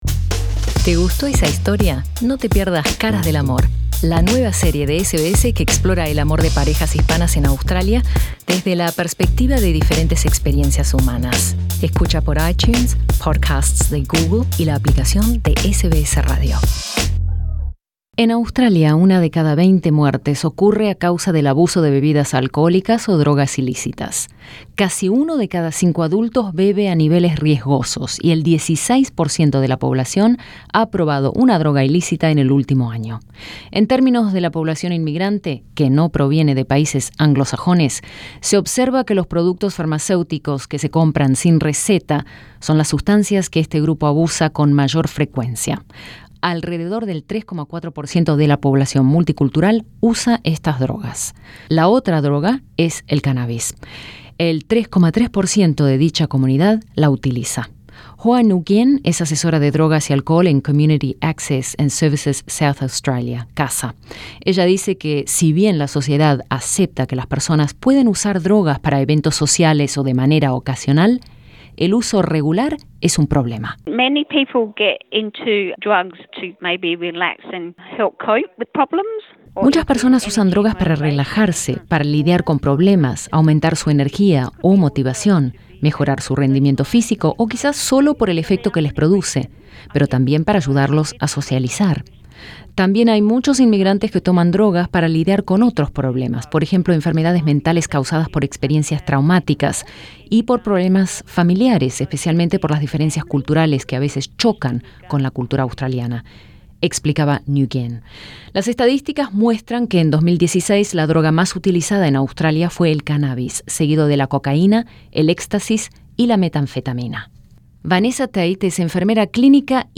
El alcohol y las drogas ilícitas son las drogas más consumidas en Australia y ambas son responsables de un elevado número de muertes. Escucha el informe de SBS en español.